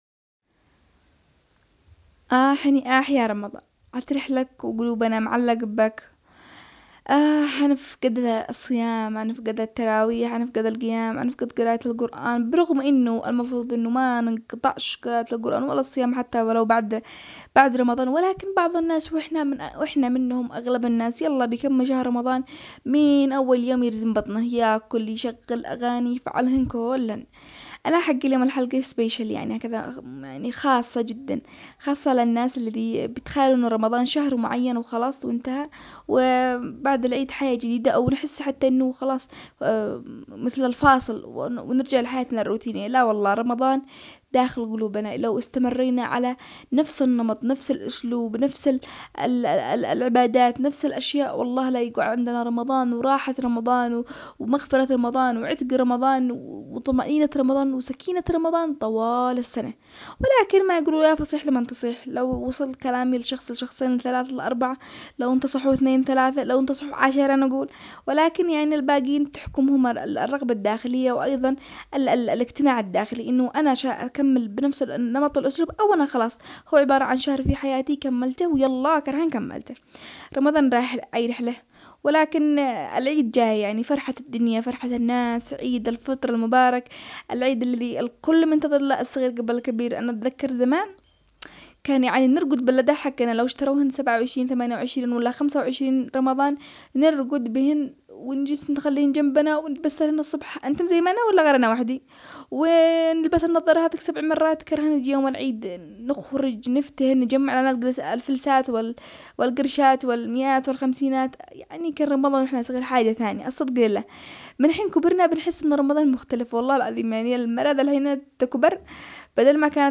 برنامج زنجبيل بغباره برنامج أسبوعي اجتماعي يناقش القضايا الخاصة بالنساء بأسلوب كوميدي و باللهجة العامية الصنعانية